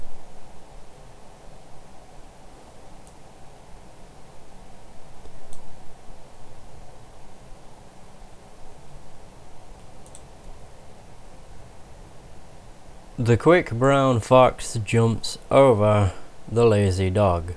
I’m trying to record commentary, the equipment i’m using is the Sony ECM-CS3 Condenser Mic.
I have 2 audio samples, one before applying noise removal & one after applying noise removal, both clips have been normalised.
I am not sure what your complaint is from reading, but from listening, the problem seems to be that some click sounds in “before” now have an audible whooshing noise in them “after.” Is that it?